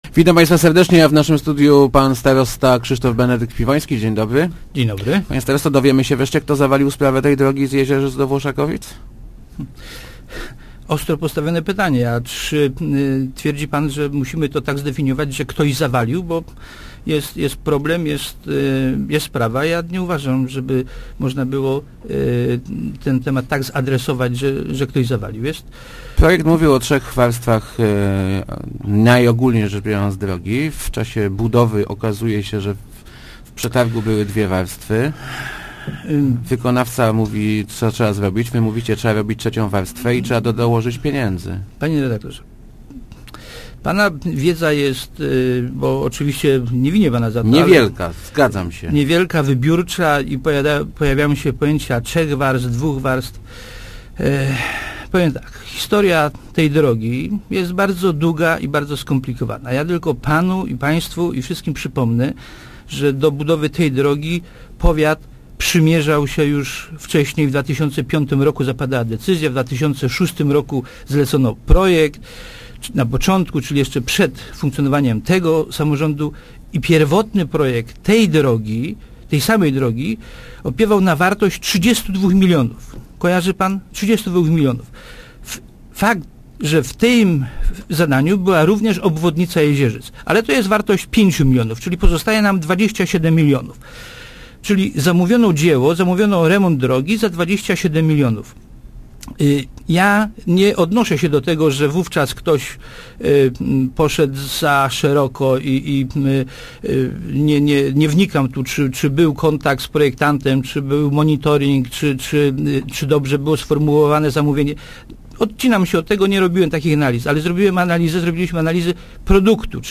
Urz�d Marsza�kowski zaakceptowa� nasz plan poradzenia sobie z problemem, który wyst�pi� podczas budowy drogi Jezierzyce Ko�cielne-W�oszakowice – mówi� w Rozmowach Elki starosta Krzysztof Benedykt Piwo�ski. Zapewni� on przy tym, �e nie ma zagro�enia utraty dofinansowania tej inwestycji.